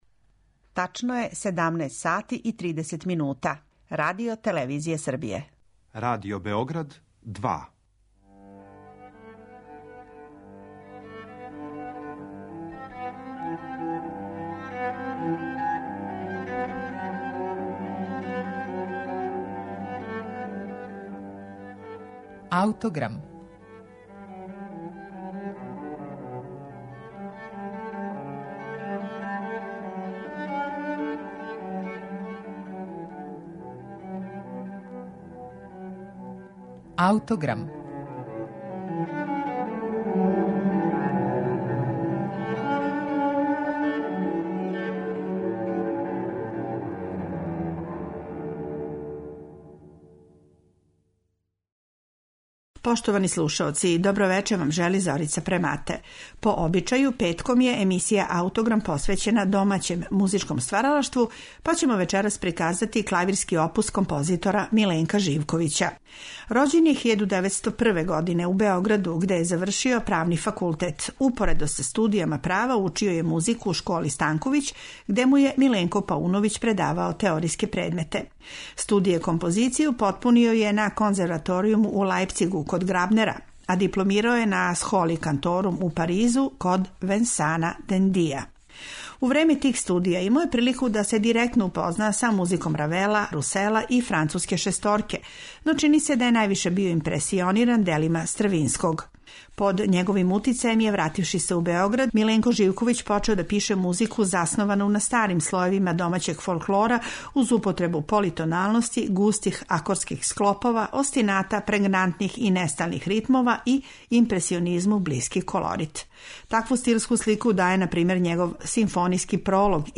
Овога пута биће представљен клавирски опус нашег композитора Миленка Живковића, који је својим делима обележио прву половину прошлог века.
а снимак је начињен на концерту којим је обележено 110 година од рођења композитора